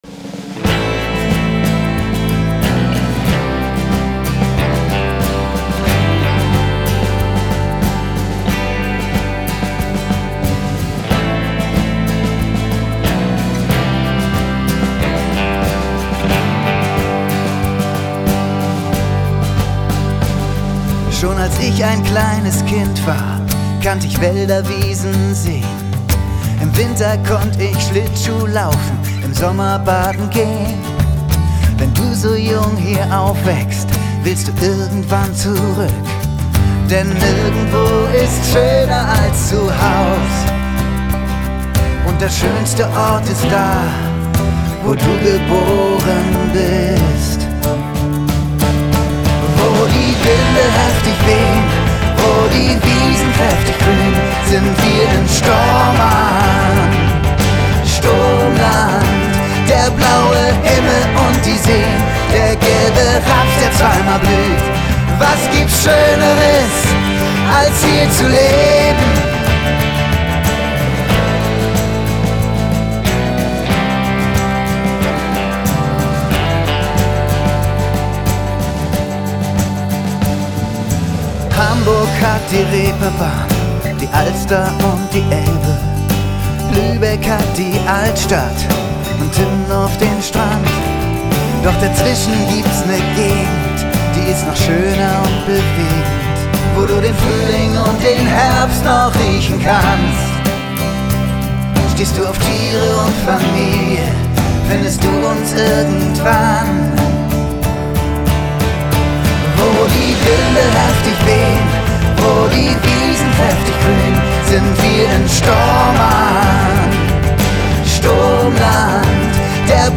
Akustikversion